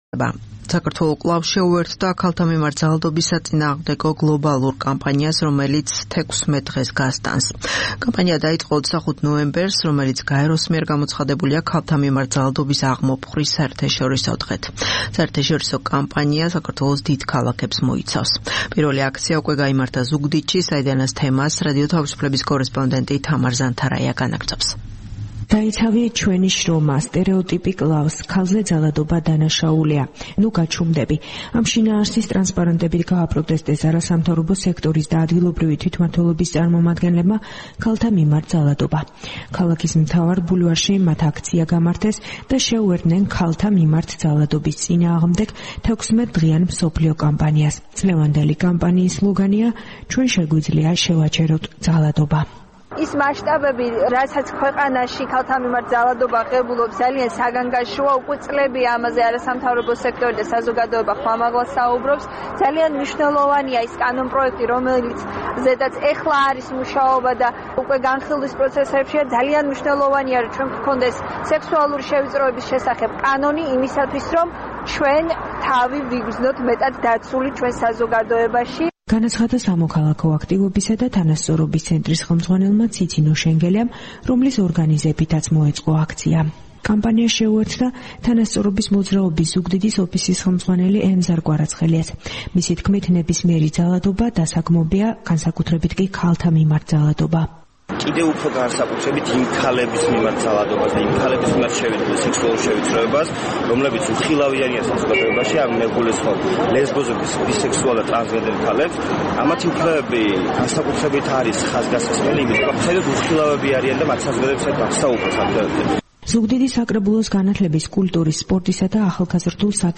საპროტესტო აქცია ზუგდიდში